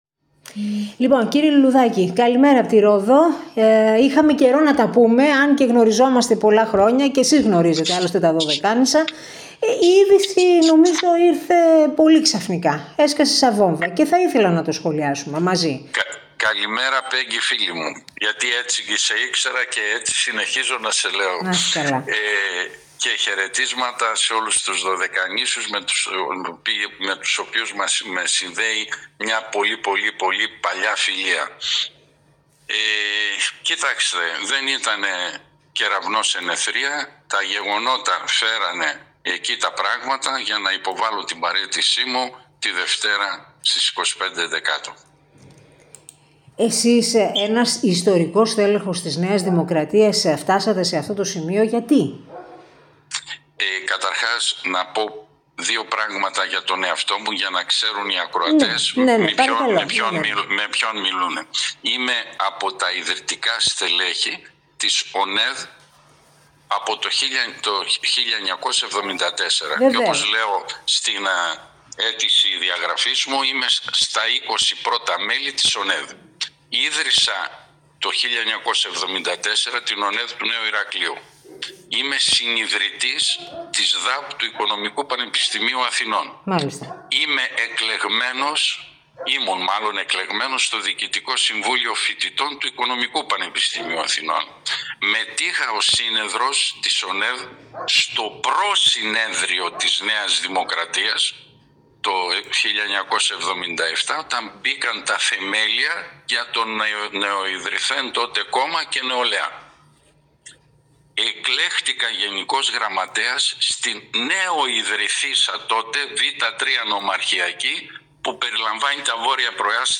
Σκληρή κριτική στον πρωθυπουργό, Κυριάκο Μητσοτάκη, άσκησε σε αποκλειστική συνέντευξη που έδωσε στον topfm1024